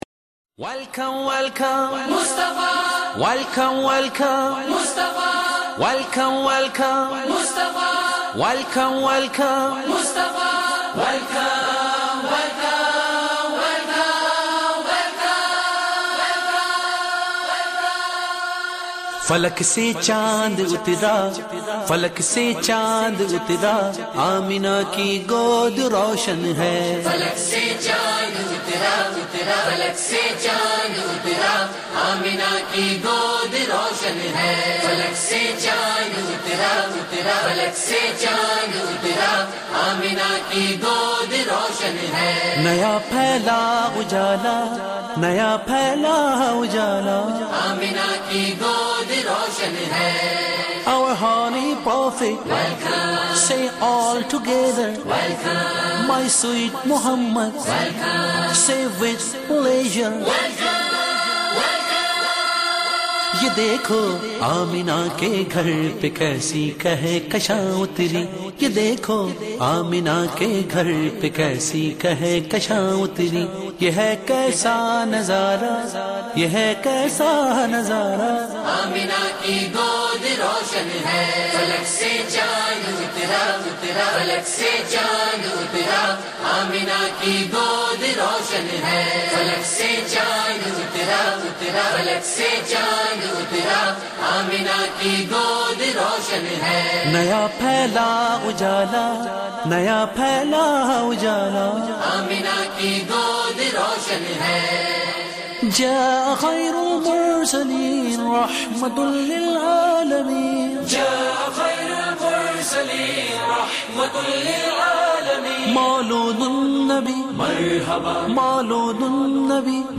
" Naat MP3